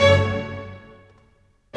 Rock-Pop 01 Strings 03.wav